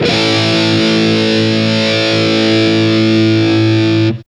Index of /90_sSampleCDs/Roland - Rhythm Section/GTR_Distorted 1/GTR_Power Chords